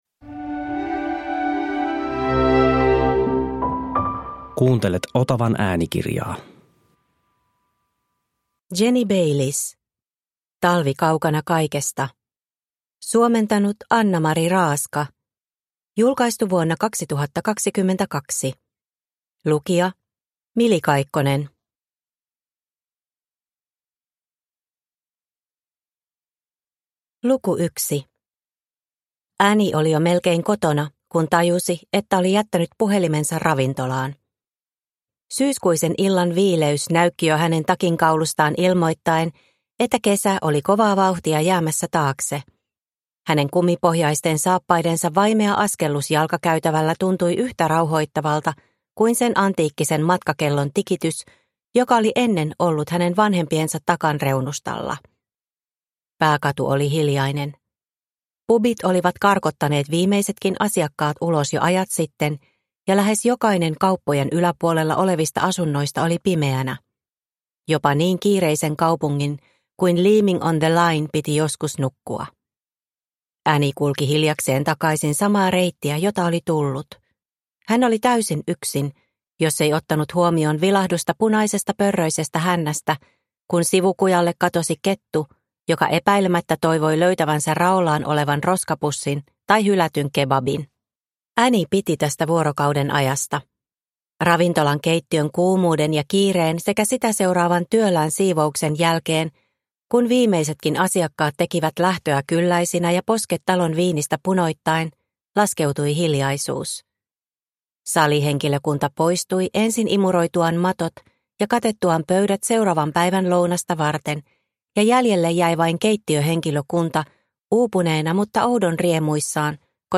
Talvi kaukana kaikesta – Ljudbok – Laddas ner